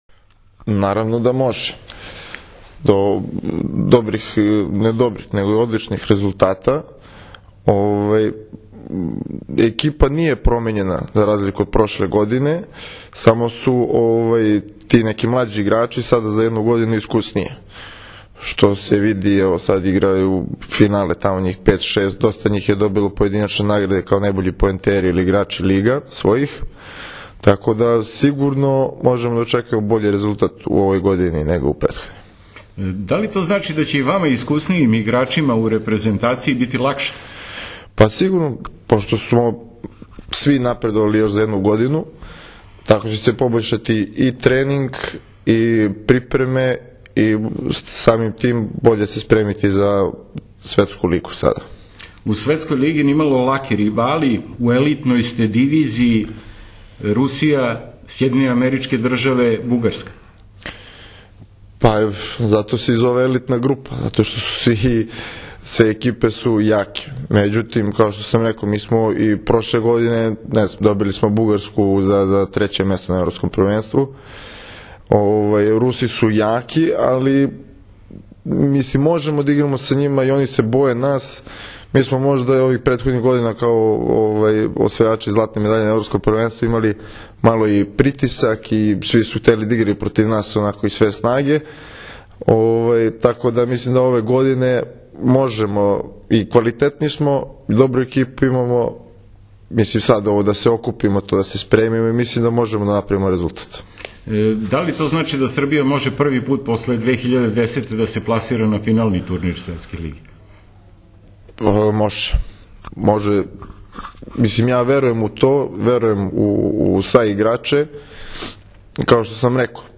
INTERVJU SA NIKOLOM KOVAČEVIĆEM